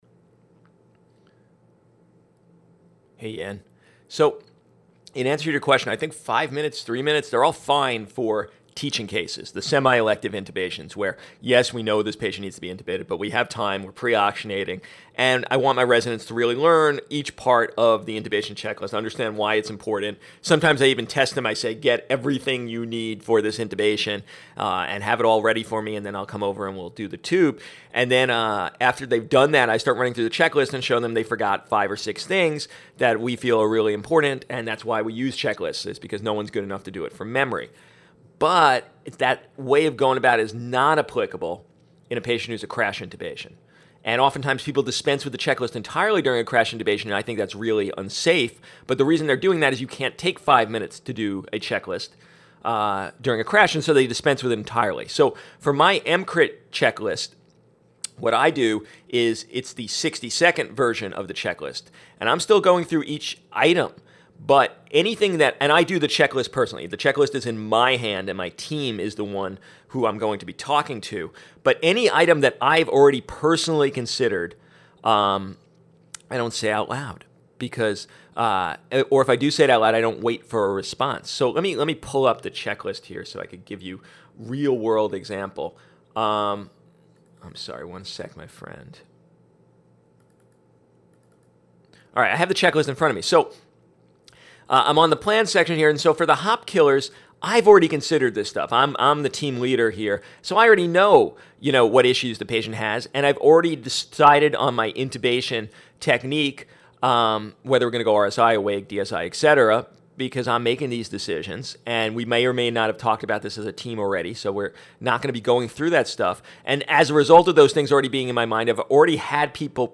This was about 2 weeks ago and he recorded an audio response to me at that time which was gold and thanks to his permission, it has to be shared!